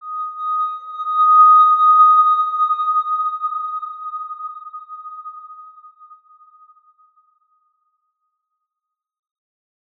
X_Windwistle-D#5-mf.wav